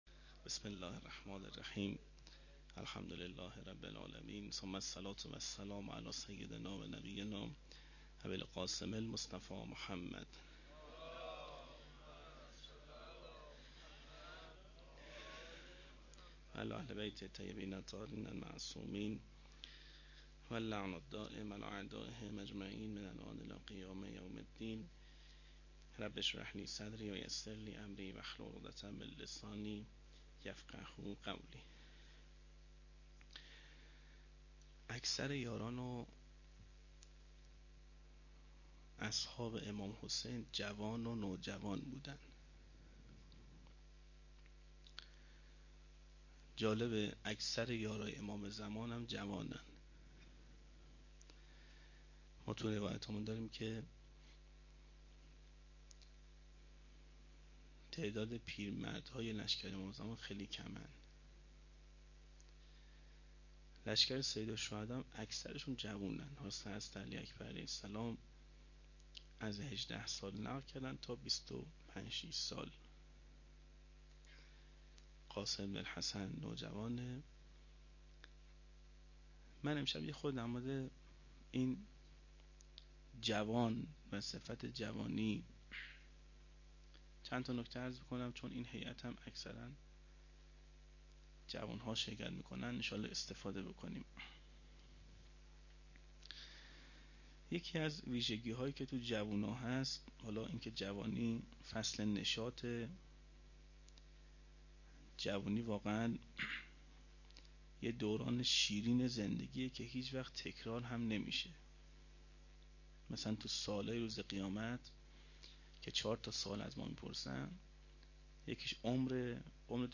01-shab8-sokhanrani.mp3